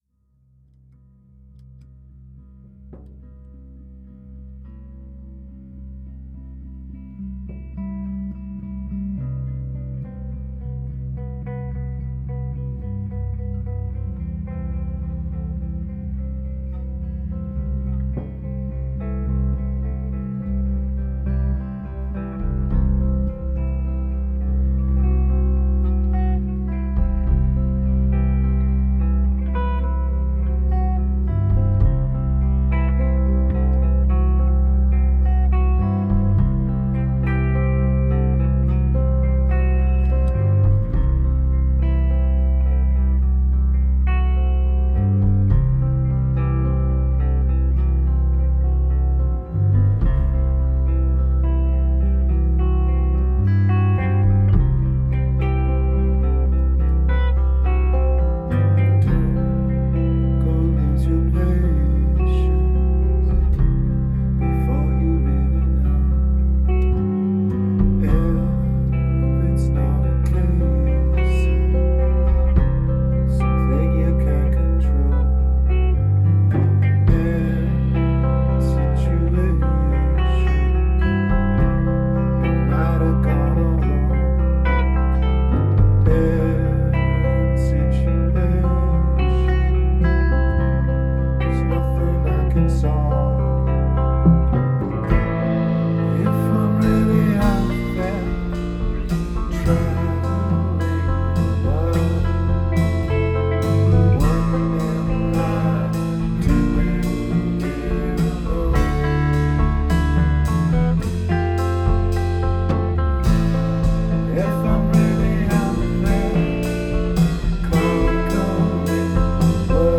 Rehearsals 30.7.2013